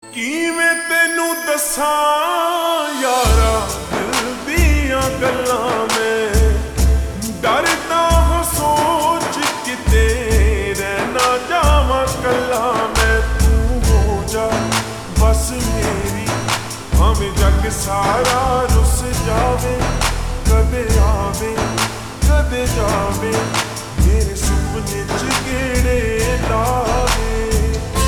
(Slowed + Reverb)
Punjabi music